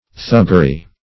Search Result for " thuggery" : Wordnet 3.0 NOUN (1) 1. violent or brutal acts as of thugs ; The Collaborative International Dictionary of English v.0.48: Thuggery \Thug"ger*y\, Thuggism \Thug"gism\, n. Thuggee.
thuggery.mp3